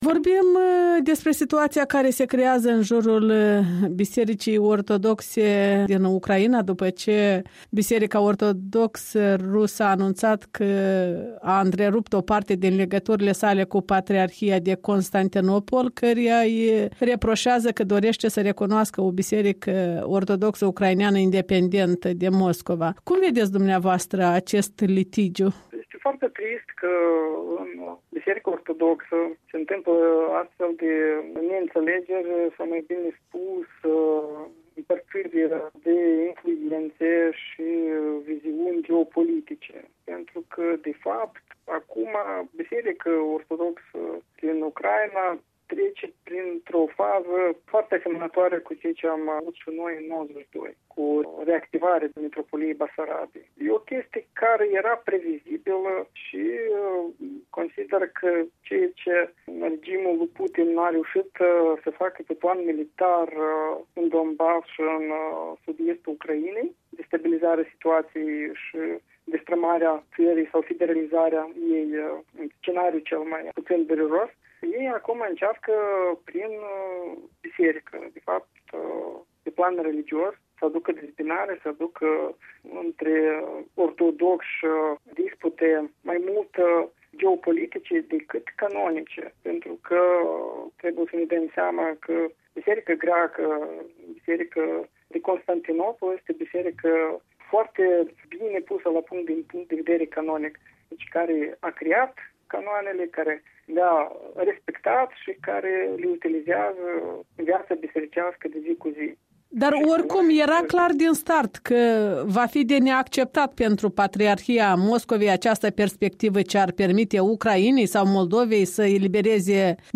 Interviu cu un teolog despre relațiile Bisericii Ortodoxe Ruse cu Ucraina și R.Moldova.